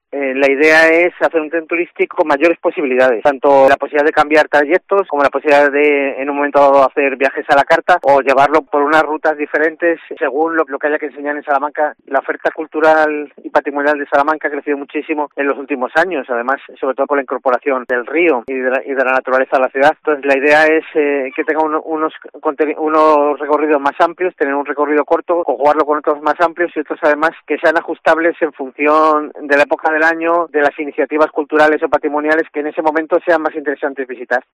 El Concejal de Turismo explica las novedades del tren turístico